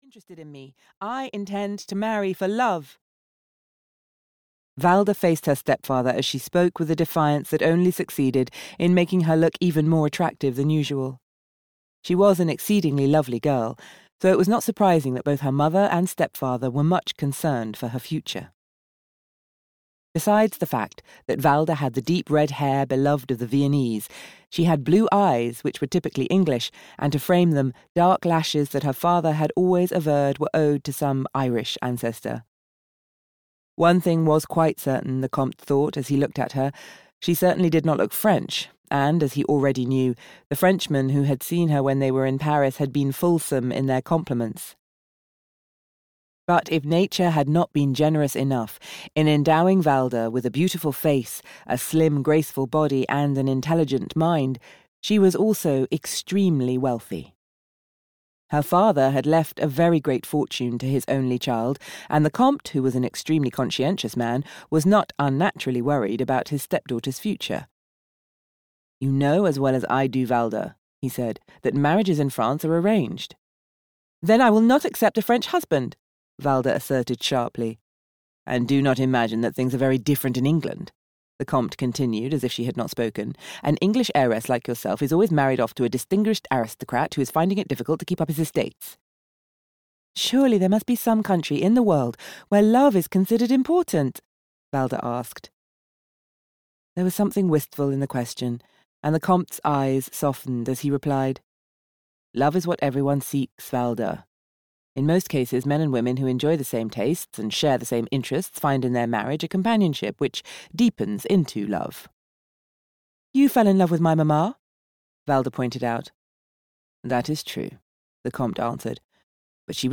Audio knihaThe Wild Cry of Love (EN)
Ukázka z knihy